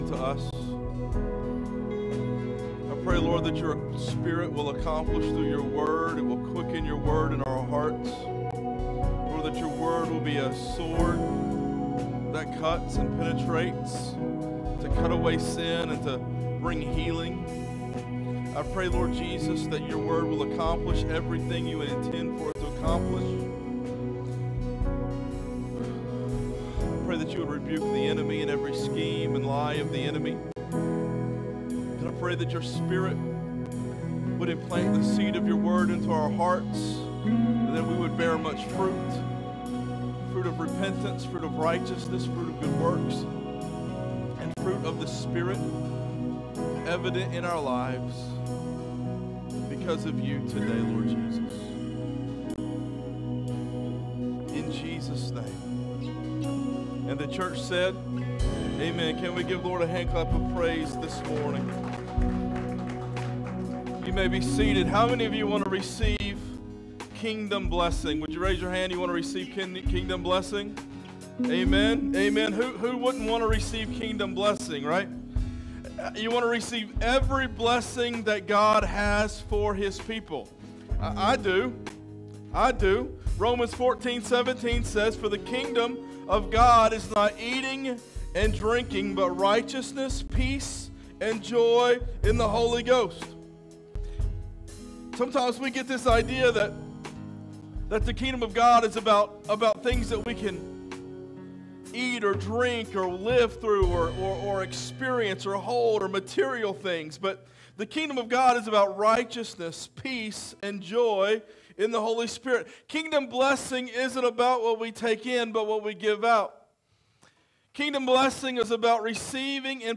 Sermons | Real Life Community Church